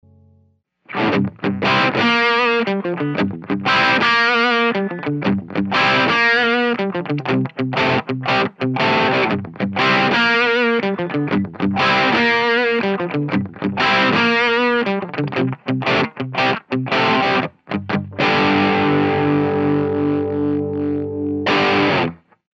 • Falante de Guitarra
Purple Hemp é o 2° falante com cone composto com fibra de cânhamo fabricado no Brasil,possui um low-end encorpado juntamente ao seus graves firmes, médios equilibrados e médios agudos suaves, tornando um falante equilibrado e sem picos evidentes.
CLUNCH
Purple-Hemp-Crunch.mp3